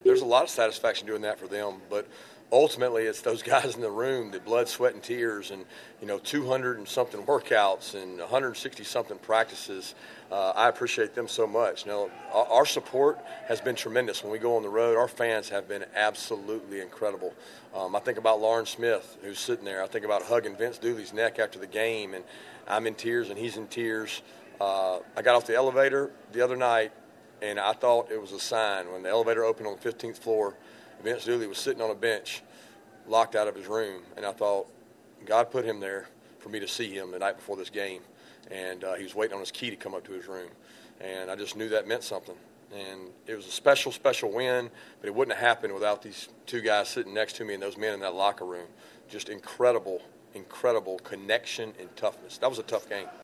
Smart recently mentioned the satisfaction of finally ending the title drought for the program.